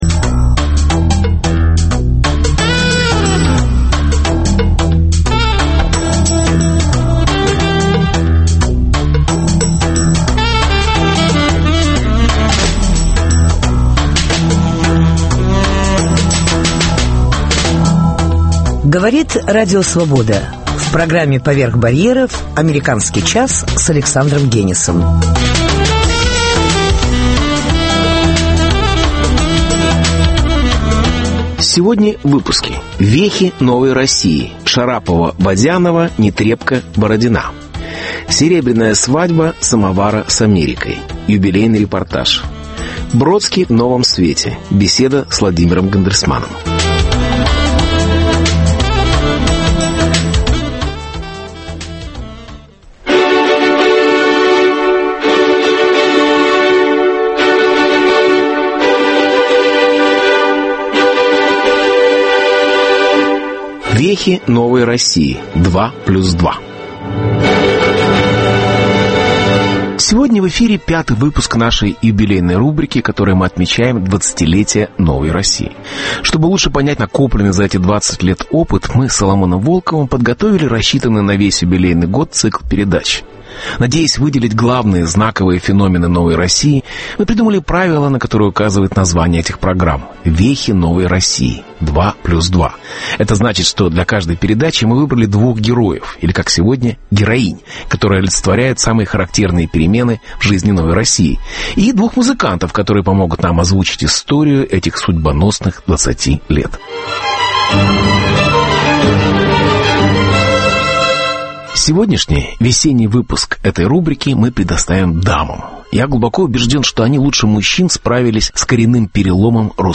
Серебряная свадьба «Самовара» с Америкой. Юбилейный репортаж.